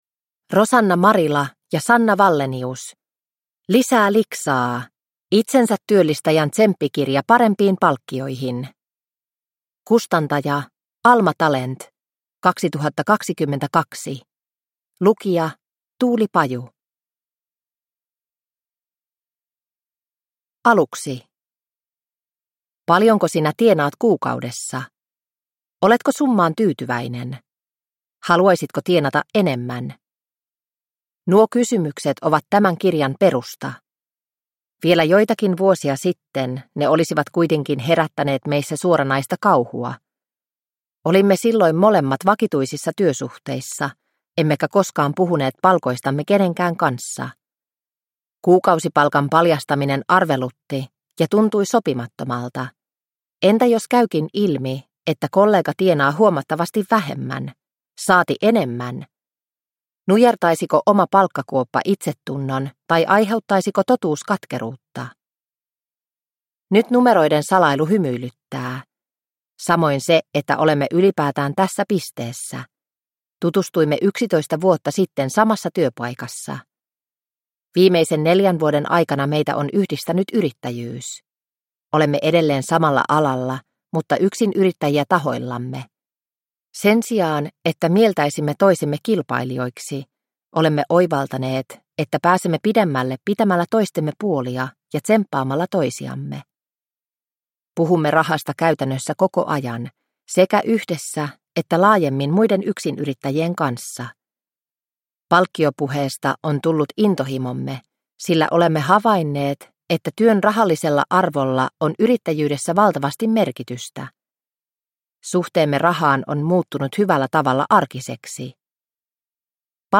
Lisää liksaa! – Ljudbok – Laddas ner